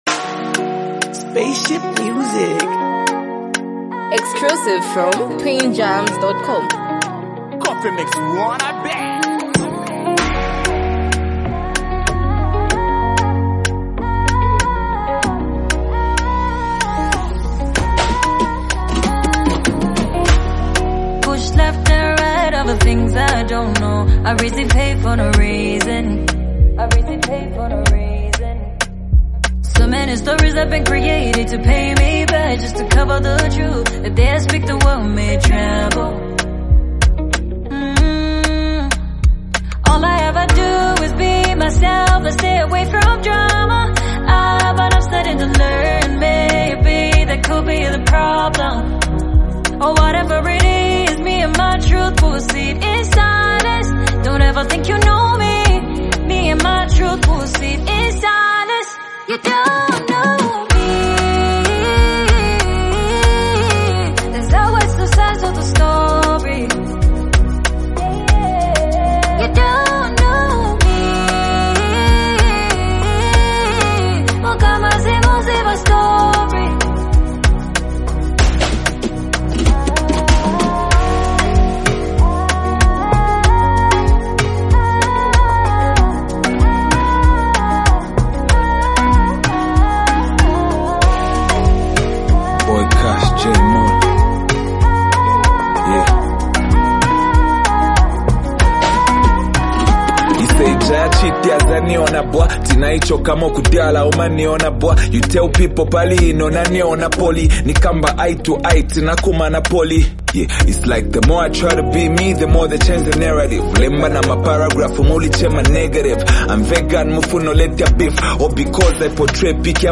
grounded and introspective rap verse
blends soulful vocals with thoughtful rap